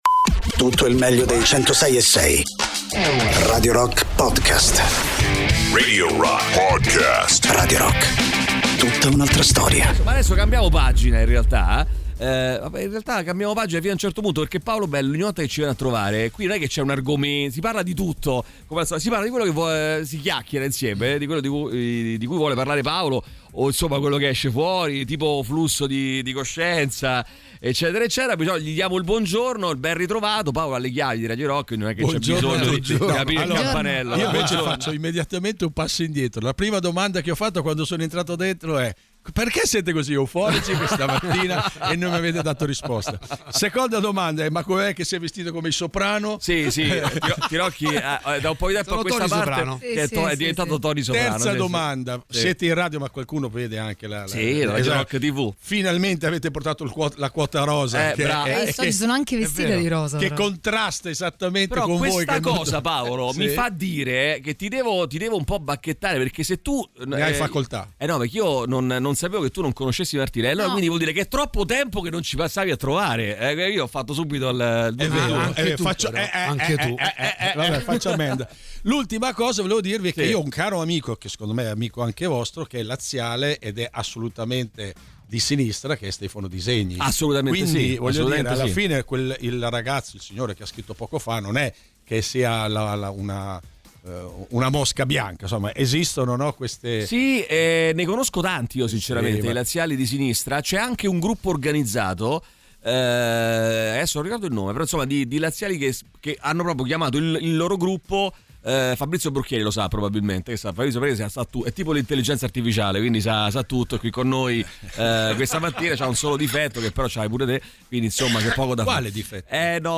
Interviste: Paolo Belli (24-03-26)